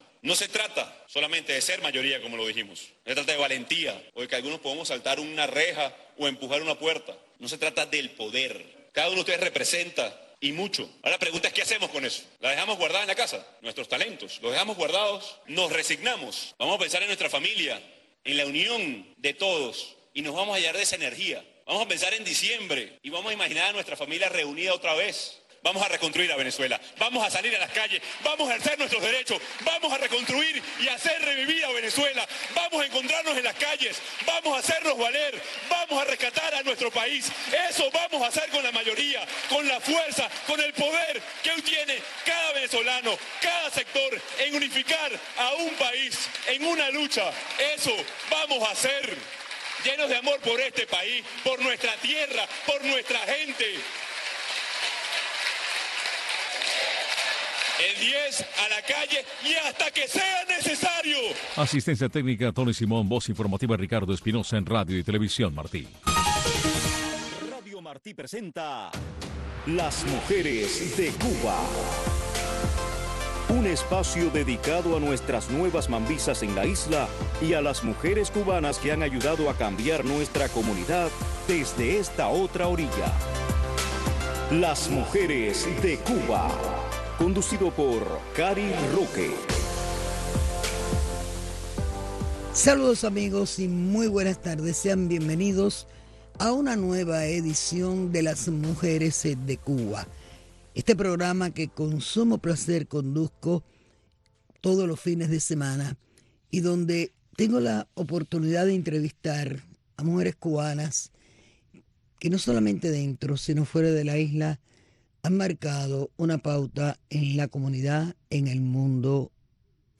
Un programa que busca resaltar a las mujeres cubanas que marcan pauta en nuestra comunidad y en la isla. Y es un acercamiento a sus raíces, sus historias de éxitos y sus comienzos en la lucha contra la dictadura. Un programa narrado en primera persona por las protagonistas de nuestra historia.